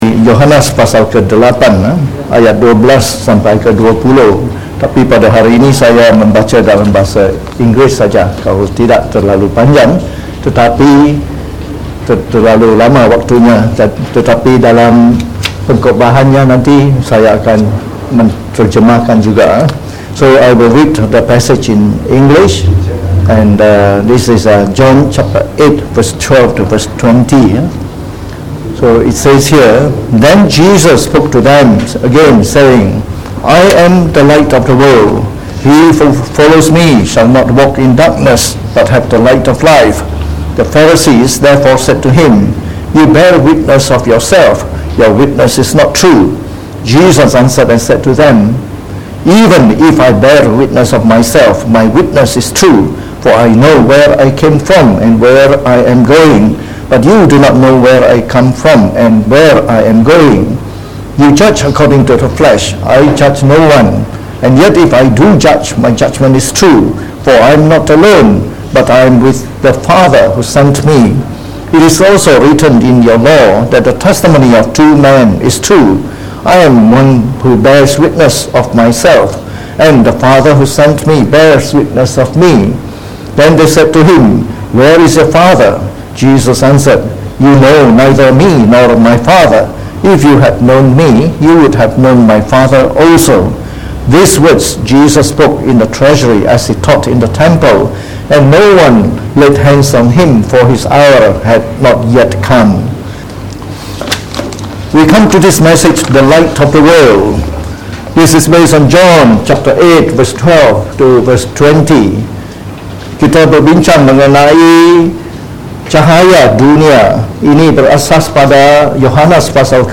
Christmas Service 2018